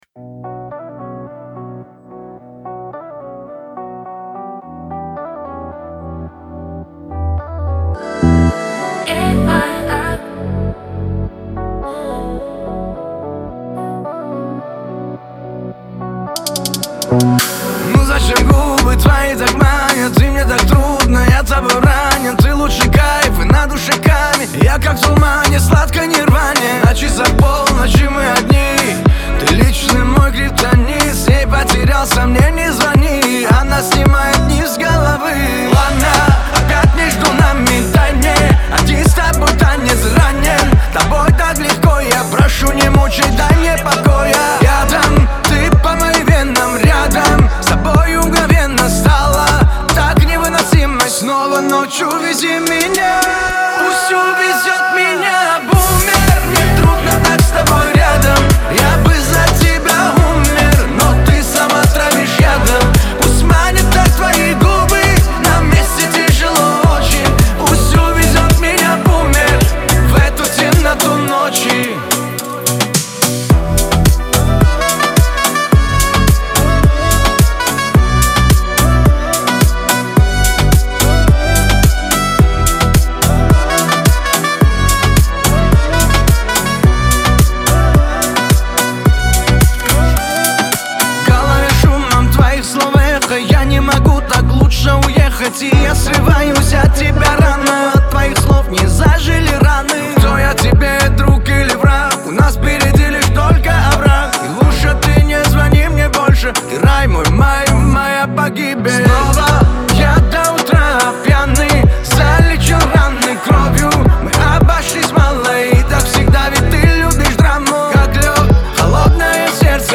Кавказ поп